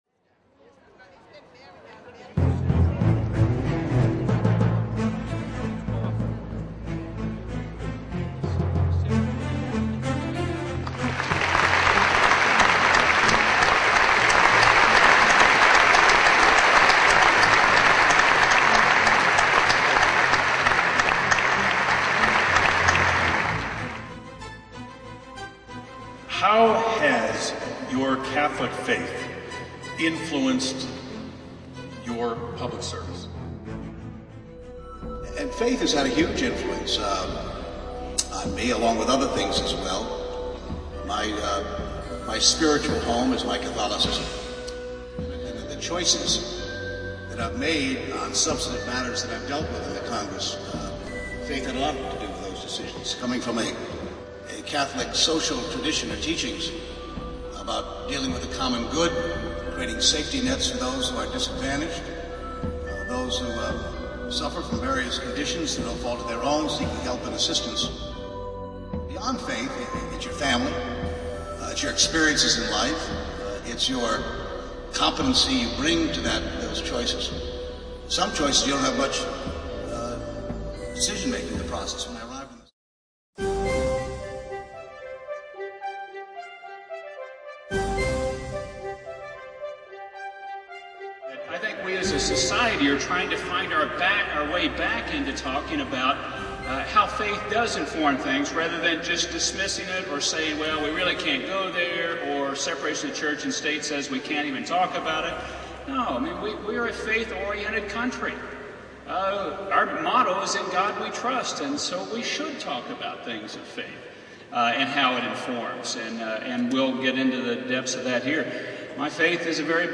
FI News #15 - Catholic Senators and Presidential Candidates, Chris Dodd and Sam Brownback discuss their Catholic Faith and it's influence on their Public Policy at Boston College's Conte Forum >>> Play Ave Maria!